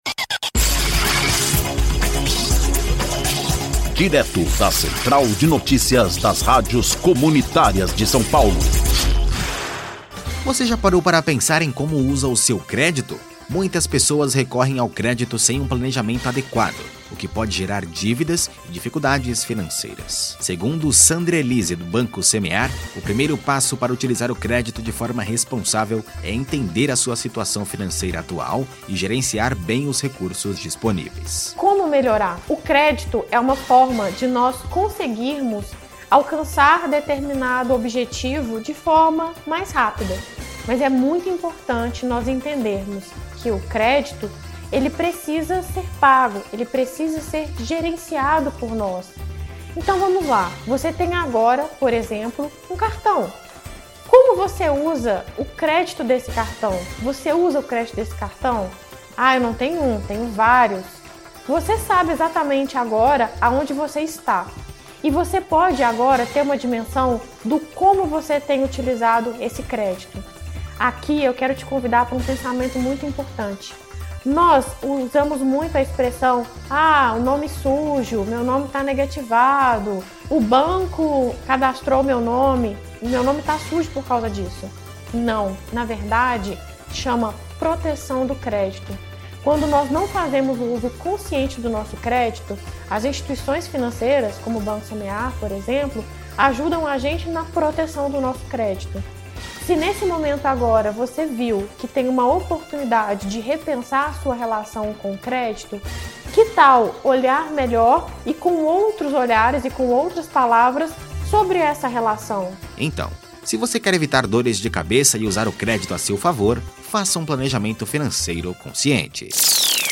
Ouça a notícia: Uso Consciente do Crédito